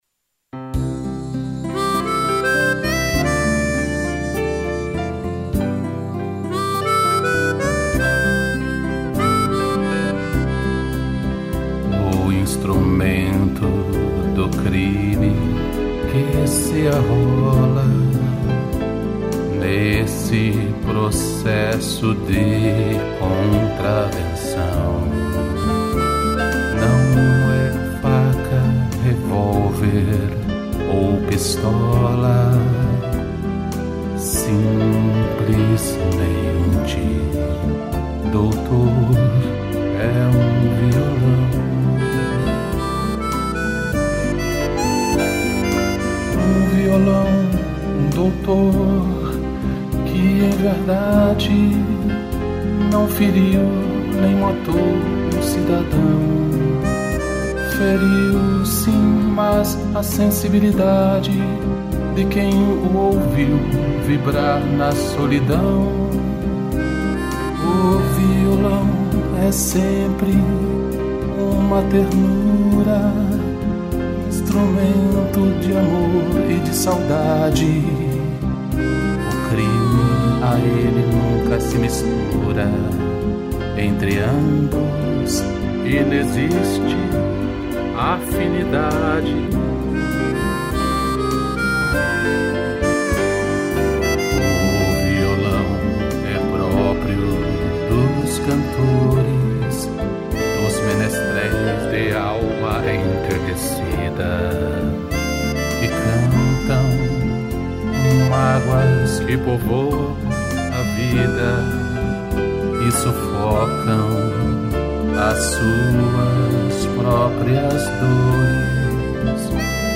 piano e gaita